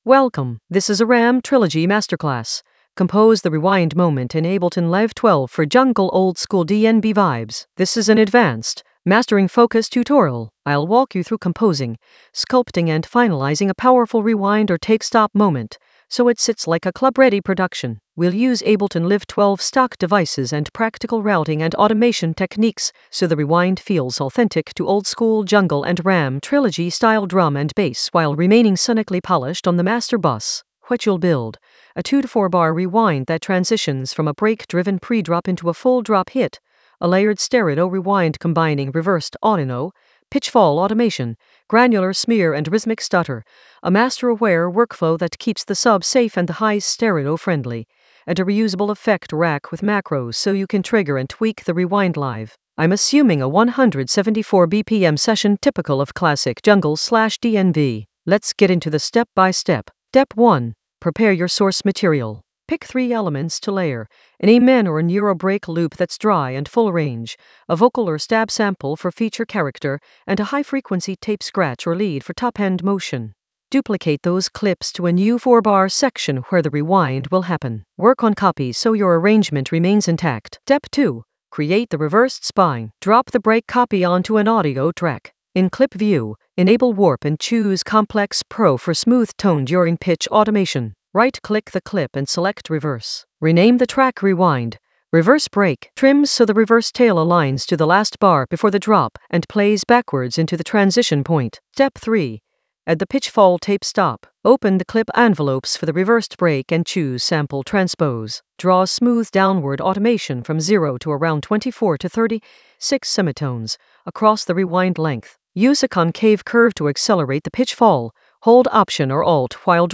An AI-generated advanced Ableton lesson focused on Ram Trilogy masterclass: compose the rewind moment in Ableton Live 12 for jungle oldskool DnB vibes in the Mastering area of drum and bass production.
Narrated lesson audio
The voice track includes the tutorial plus extra teacher commentary.